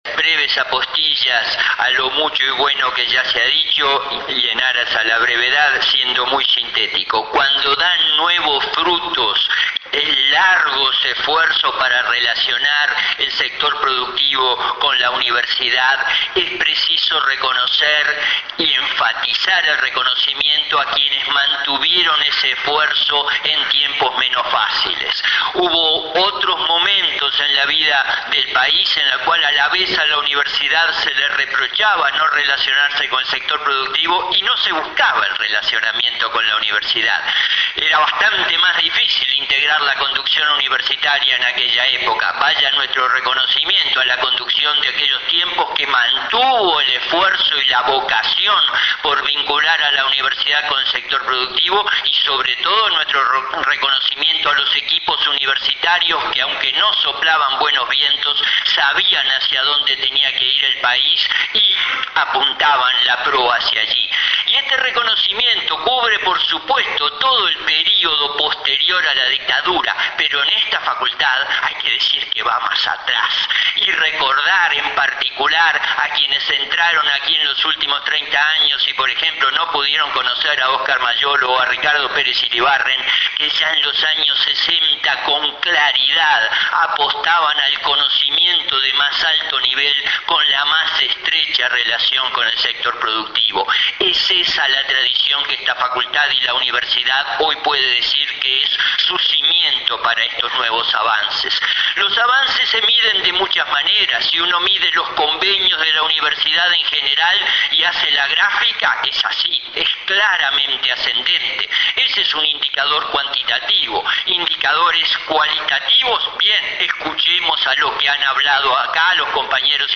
Ambos desarrollos fueron presentados en el marco del evento “Transferencia Tecnológica Universidad – Sector Productivo”, organizado por la Facultad de Ingeniería y la Fundación Julio Ricaldoni.